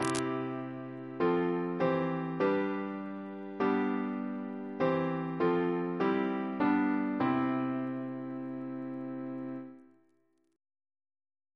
Single chant in C Composer: John Larkin Hopkins (1820-1873) Reference psalters: ACB: 12